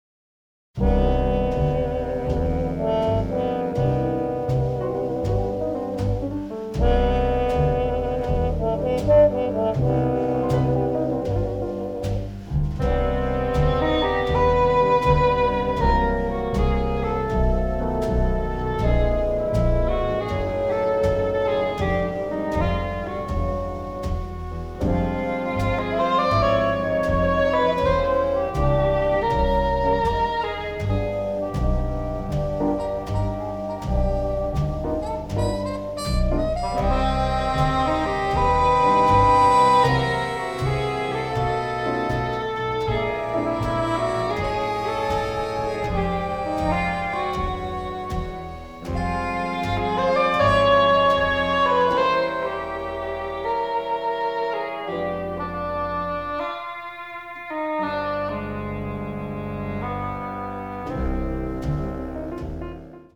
composed for jazz ensembles